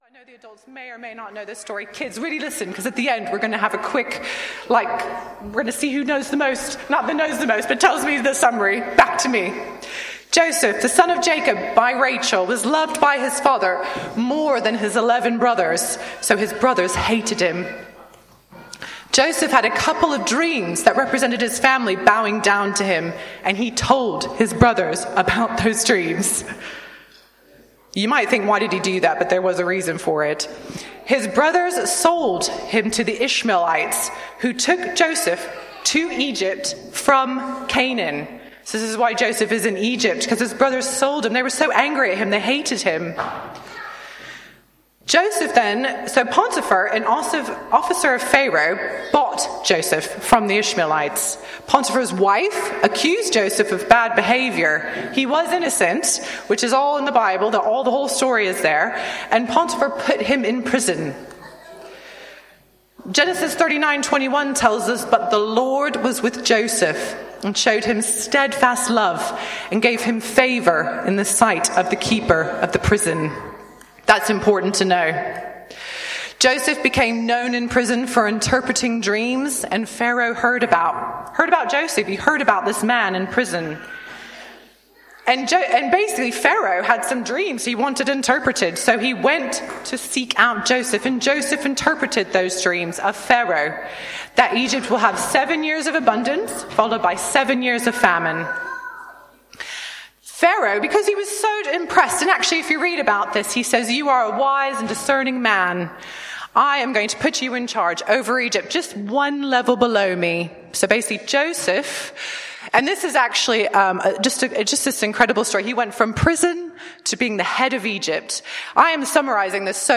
Joseph, Moses and God shows up, Bassett Street Sermons